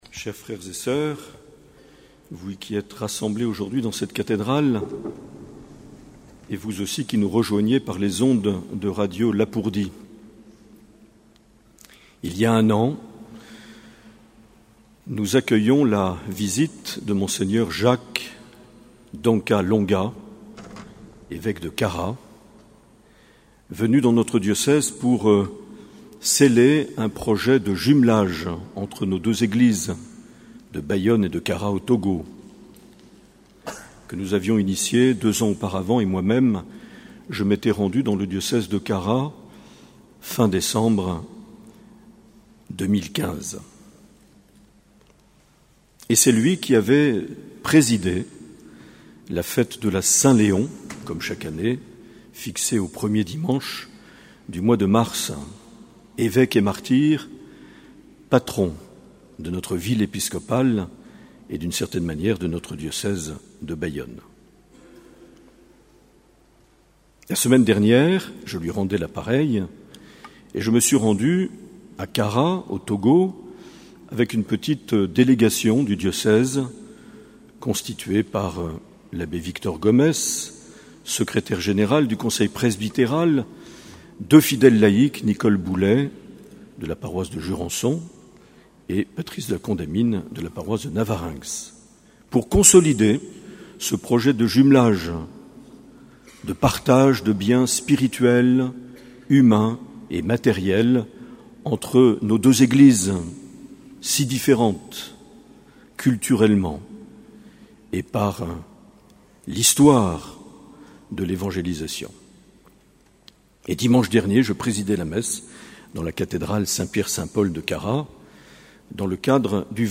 3 mars 2019 - Cathédrale de Bayonne - Saint Léon et admission au Diaconat permanent
Accueil \ Emissions \ Vie de l’Eglise \ Evêque \ Les Homélies \ 3 mars 2019 - Cathédrale de Bayonne - Saint Léon et admission au Diaconat (...)
Une émission présentée par Monseigneur Marc Aillet